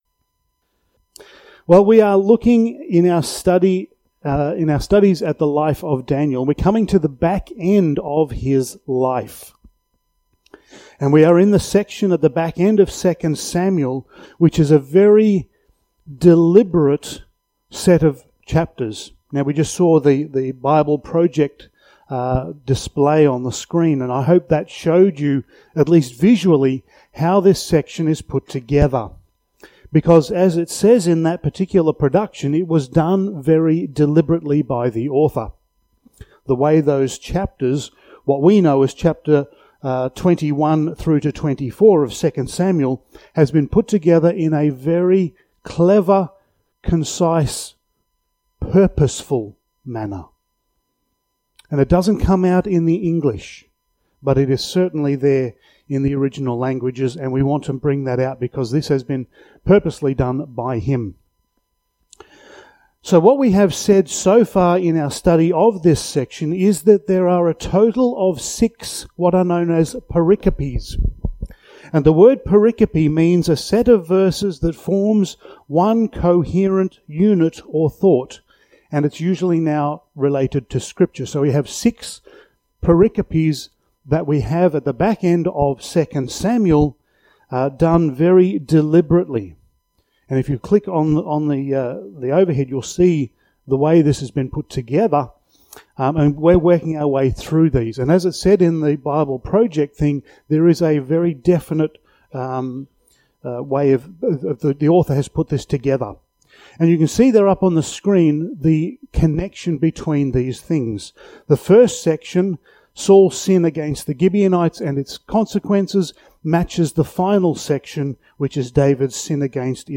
Passage: 2 Samuel 22:1-51 Service Type: Sunday Morning